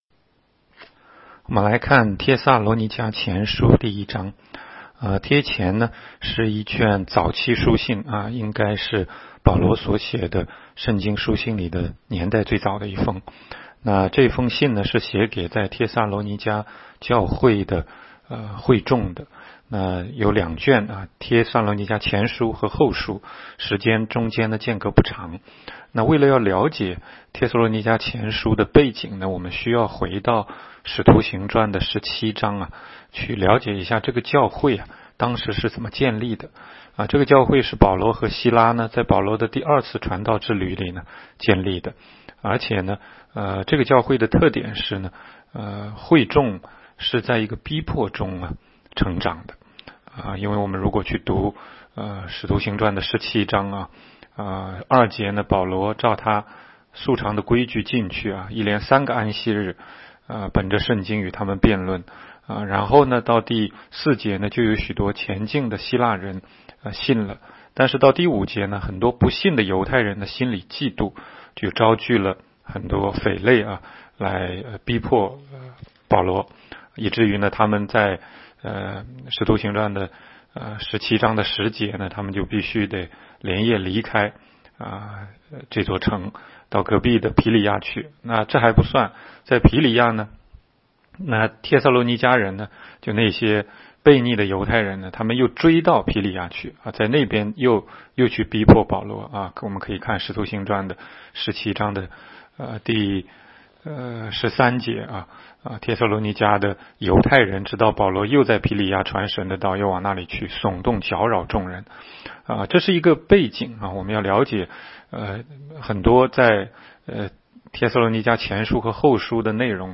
16街讲道录音 - 每日读经-《帖撒罗尼迦前书》1章